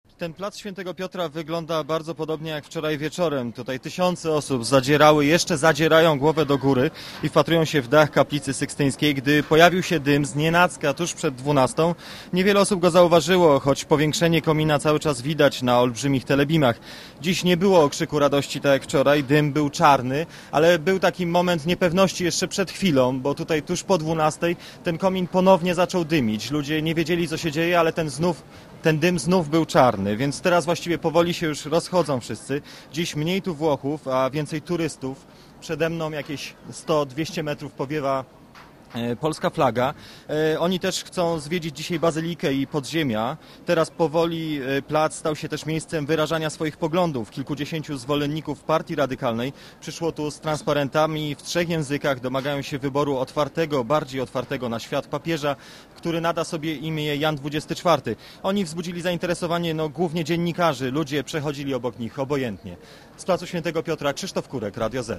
z Watykanu